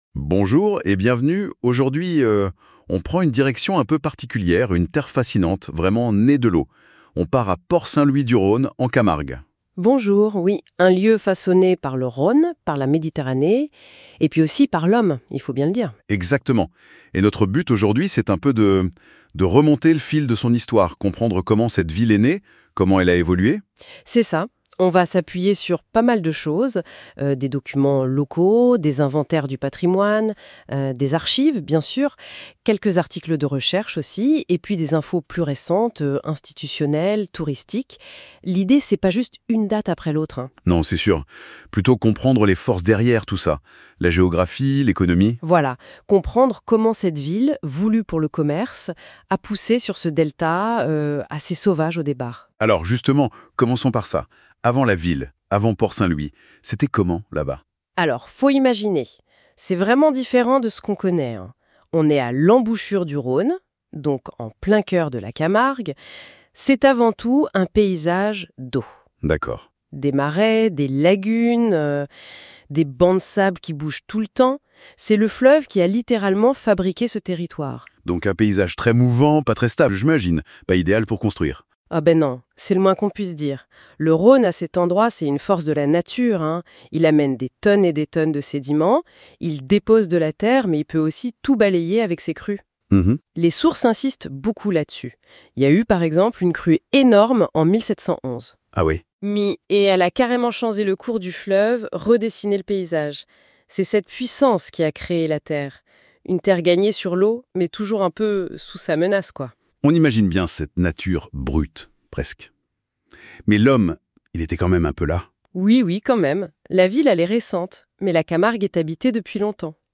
Témoignages et archives plongent l’auditeur dans l’histoire d’une cité qui s’est construite non pas contre le fleuve, mais avec lui.